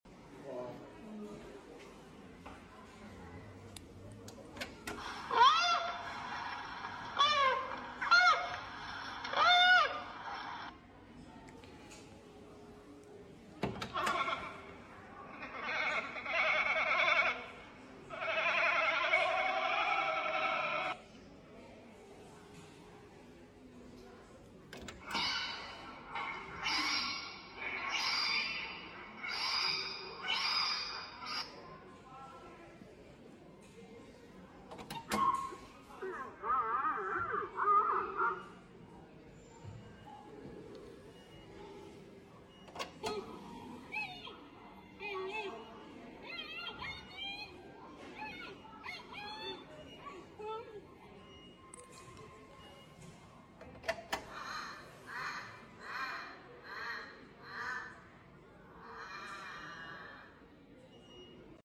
Differentiate the sound made by panda. Cheng Du Panda Centre